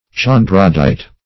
Search Result for " chondrodite" : The Collaborative International Dictionary of English v.0.48: Chondrodite \Chon"dro*dite\, n. [Gr. cho`ndros a grain (of wheat or spelt), cartilage.]